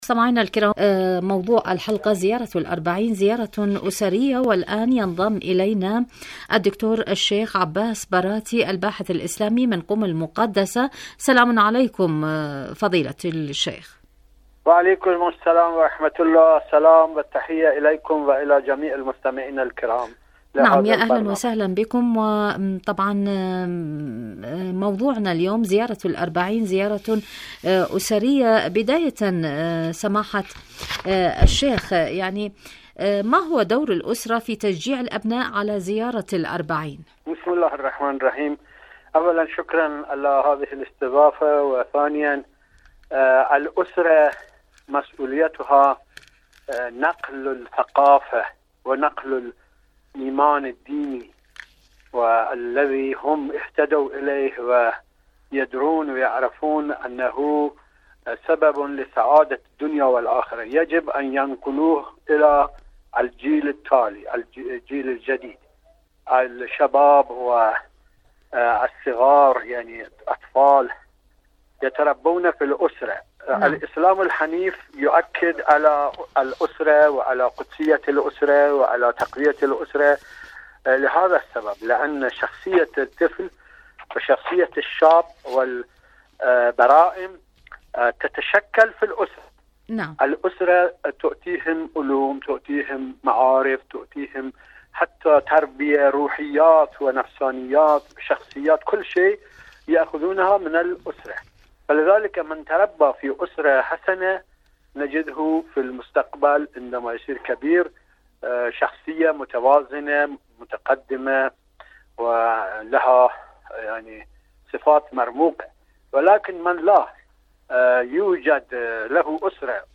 برنامج معكم على الهواء مقابلات إذاعية